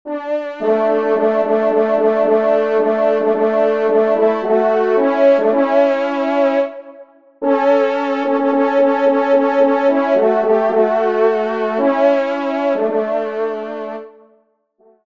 Genre : Musique Religieuse pour  Quatre Trompes ou Cors
Pupitre 3° Trompe